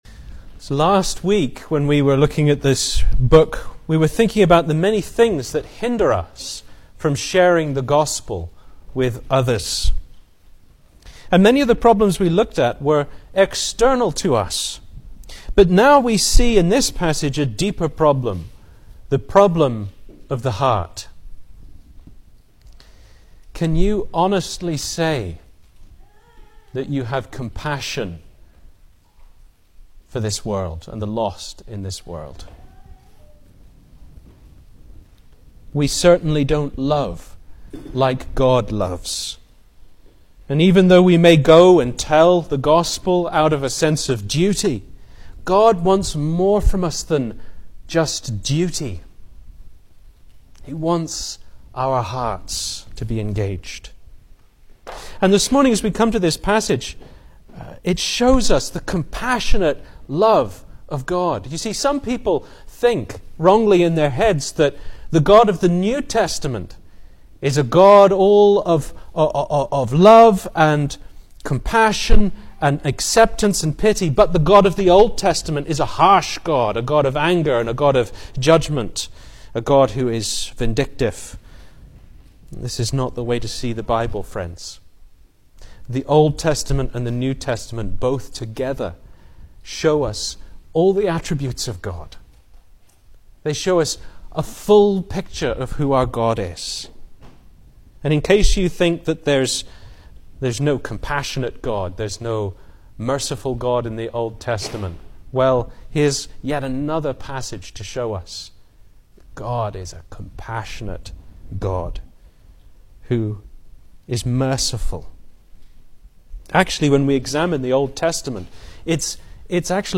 2024 Service Type: Sunday Morning Speaker